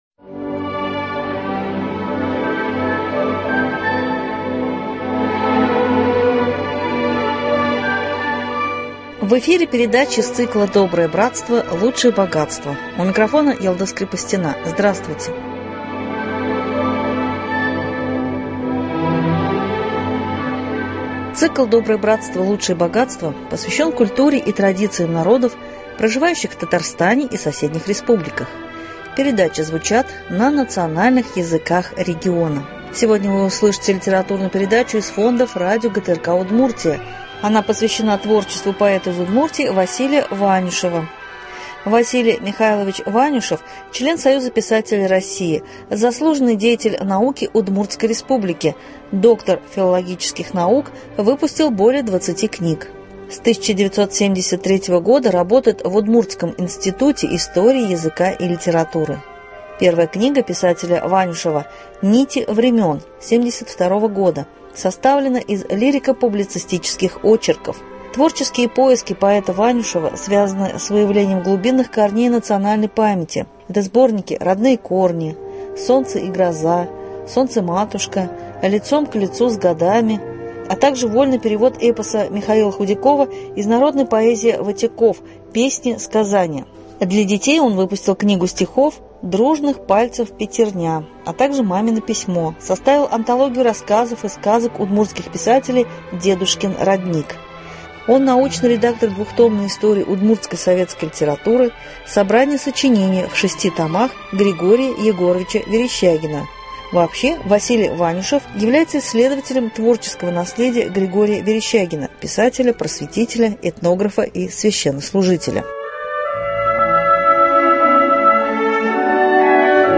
Сегодня вы услышите литературную передачу из фонда радио ГТРК Удмуртия.